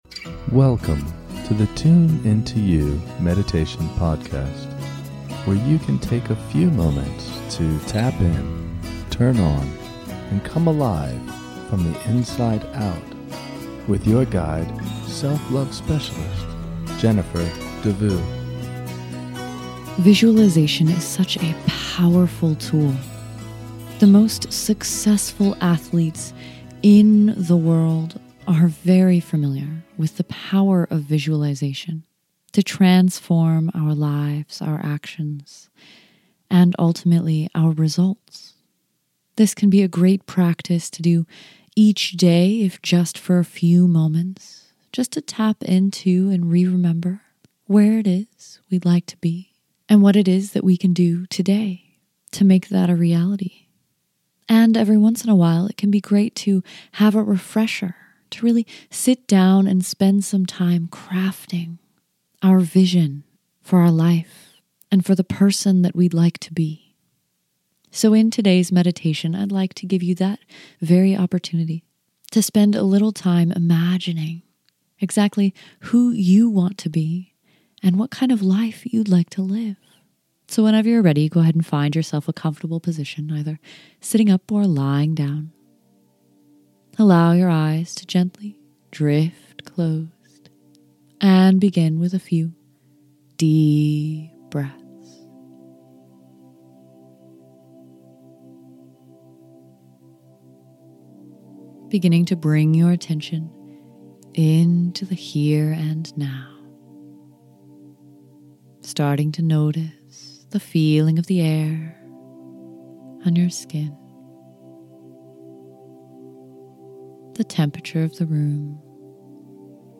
In this short guided meditation, you can connect with and discover your version of a life worth living. Connect with your perfect day so that you can begin moving towards the life you truly want to live.